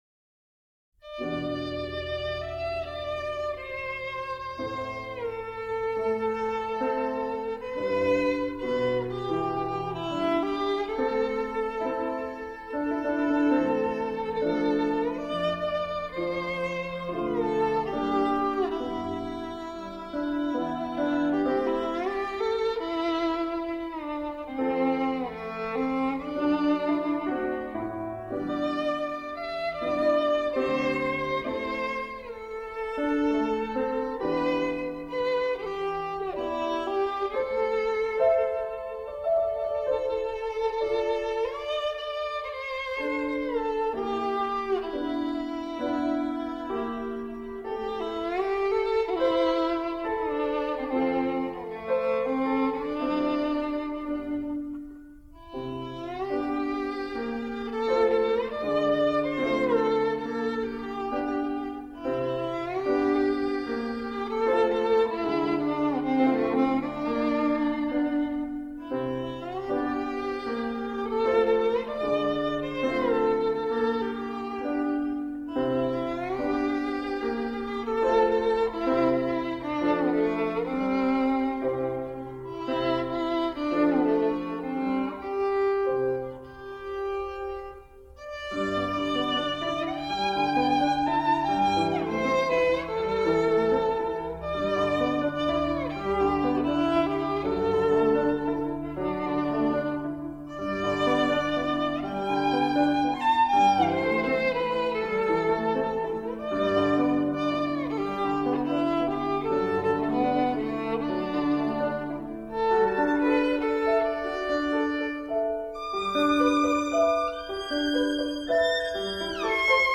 小提琴独奏